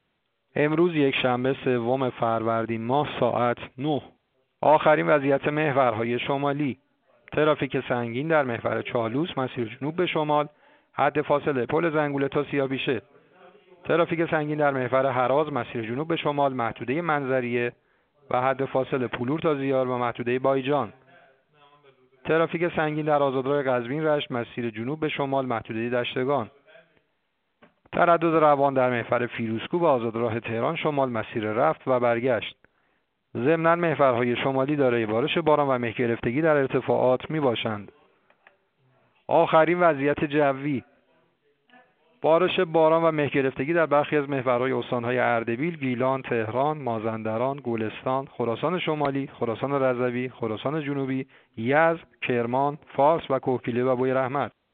گزارش رادیو اینترنتی از آخرین وضعیت ترافیکی جاده‌ها ساعت ۹ سوم فروردین؛